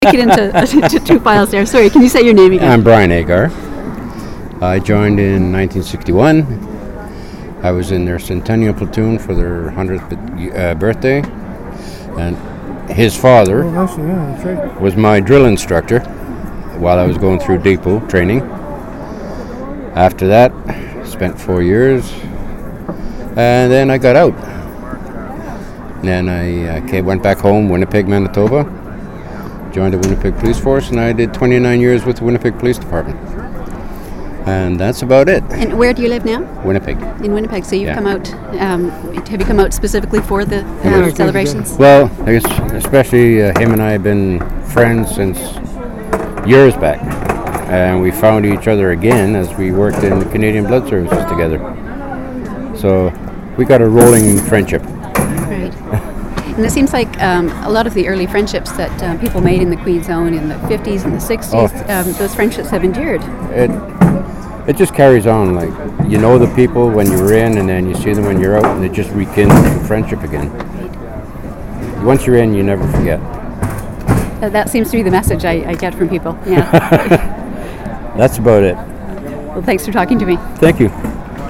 • Interview took place during the Queen's Own Rifles of Canada Vancouver Island Branch 150th Anniversary Celebration.
• Canadian Military Oral History Collection